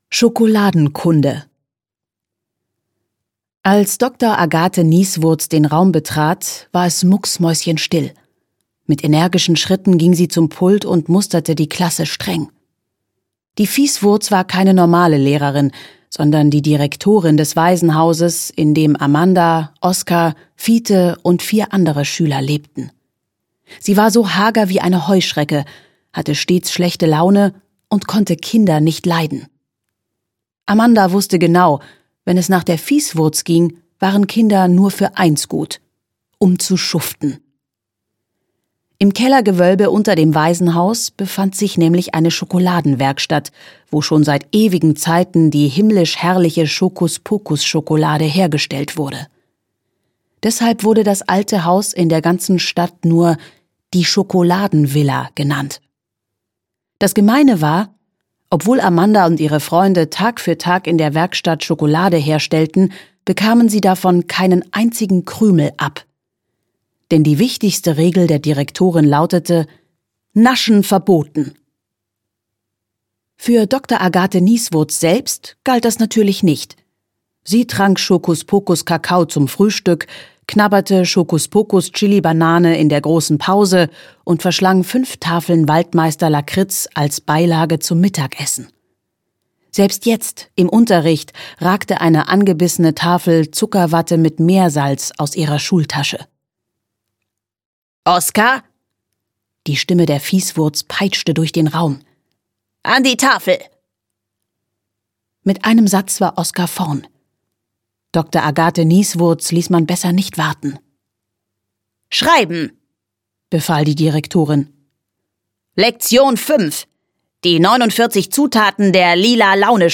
Schokuspokus 2: Wahnsinnig vanillig - Maja von Vogel - Hörbuch